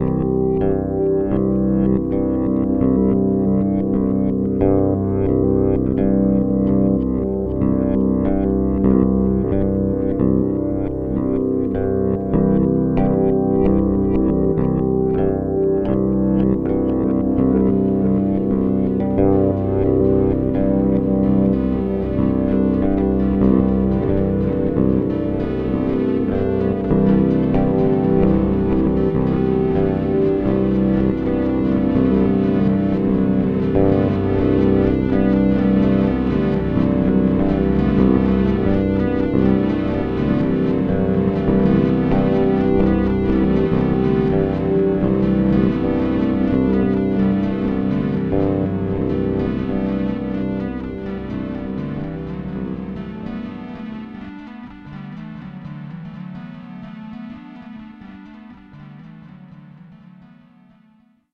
Best heard from some distance.